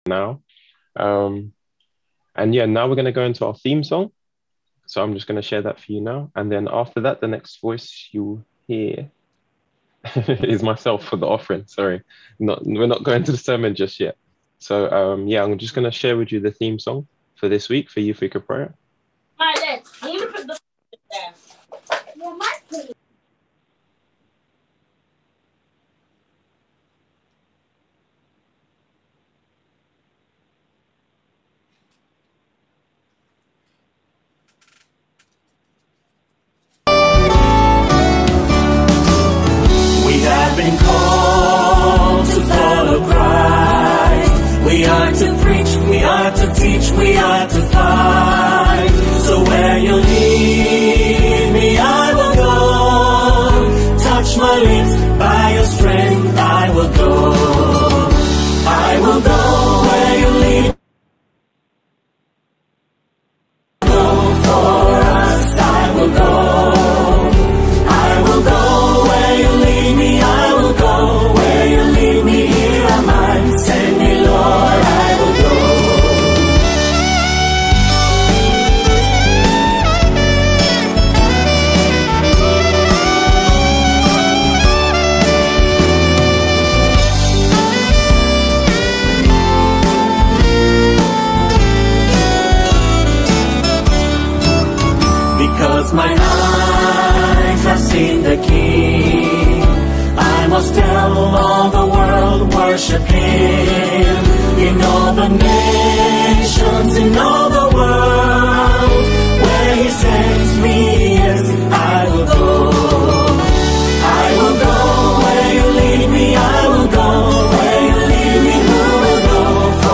on 2024-02-14 - Sabbath Sermons